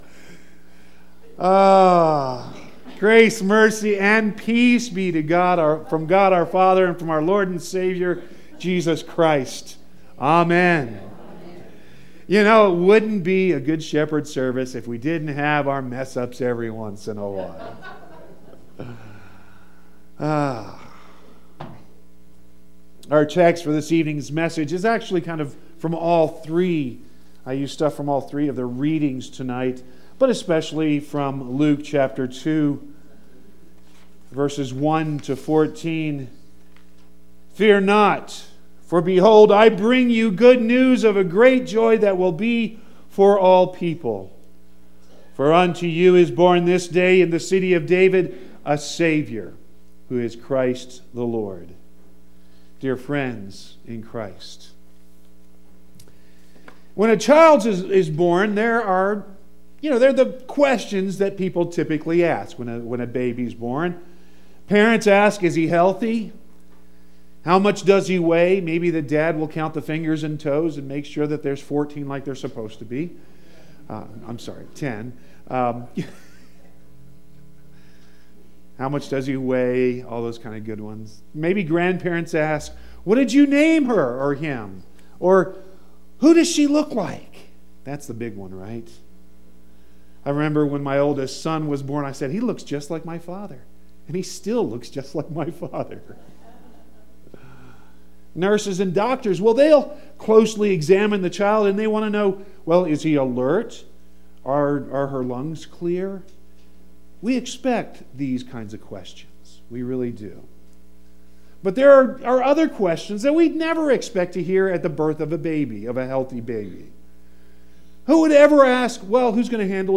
Christmas Eve 12.24.18